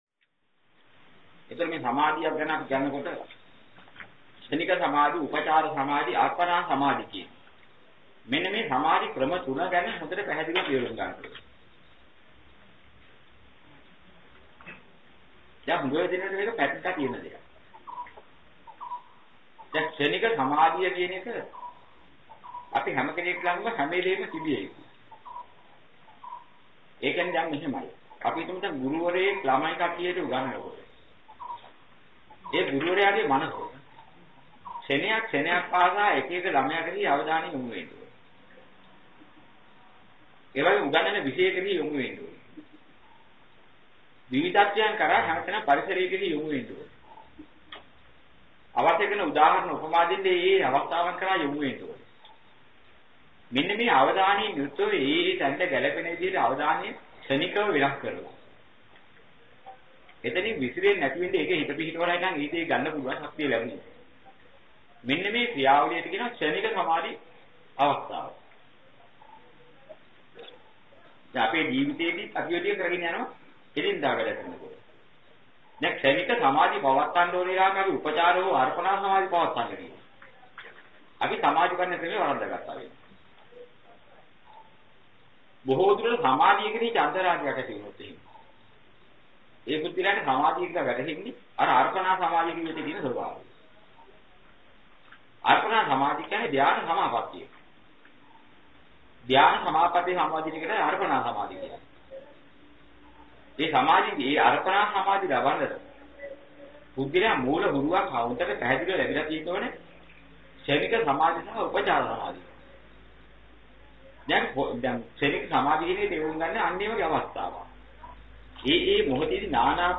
ධර්ම දේශනා.